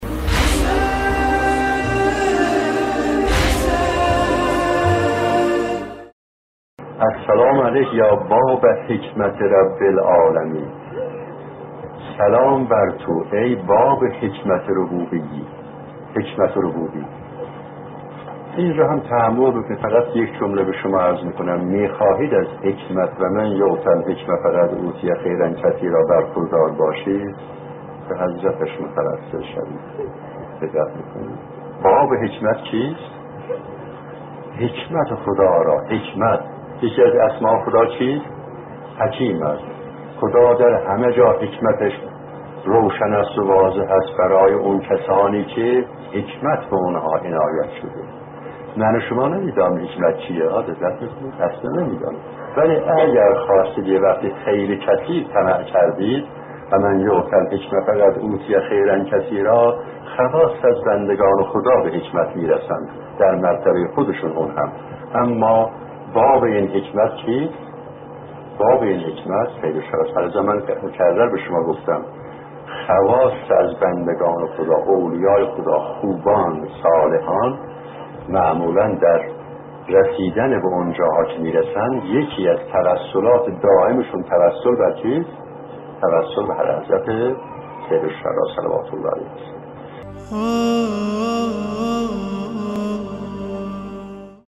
ایکنا به مناسبت ایام عزاداری امام حسین(ع) و یاران با وفایشان، مجموعه شنیداری «چراغ هدایت» را بر اساس سخنرانی‌ها و کتاب چراغ هدایت نوشته مرحوم آیت‌الله محمد شجاعی منتشر می‌کند. در ادامه، قسمت هشتم این سلسله‌گفتار را با عنوان «باب حکمت ربوبیت» می‌شنوید.